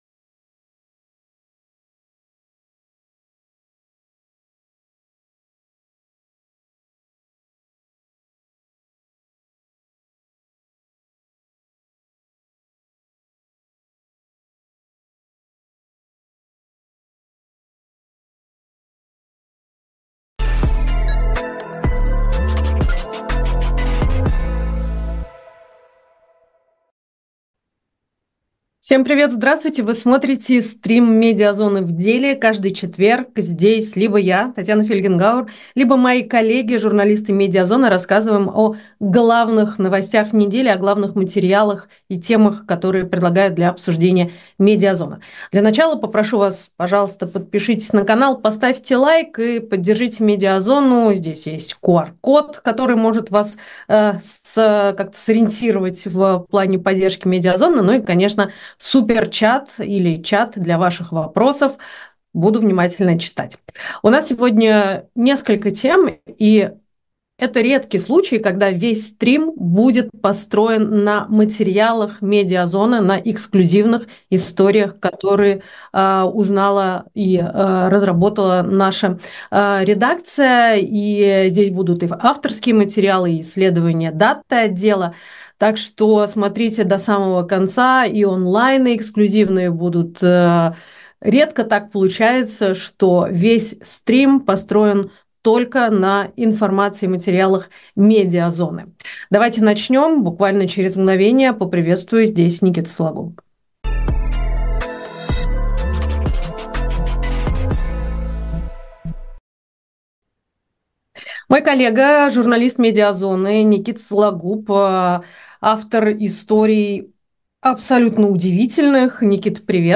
Стрим «Медиазоны» ведёт Татьяна Фельгенгауэр